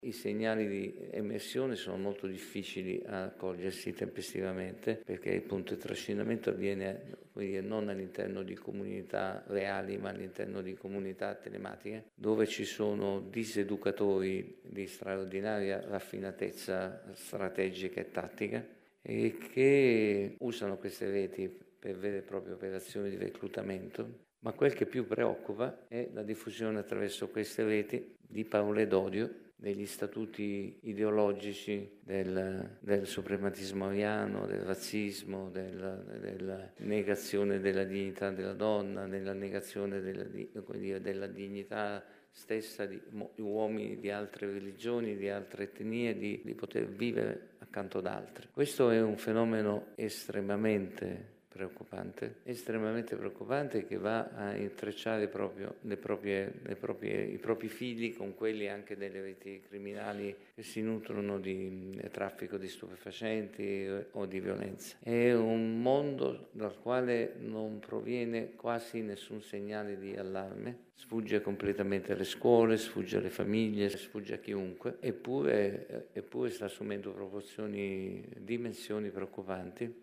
Nel corso del convegno è intervenuto il Procuratore nazionale antimafia Giovanni Melillo, che ha spiegato come le giovani generazioni siano ancora attratte da atteggiamenti criminali.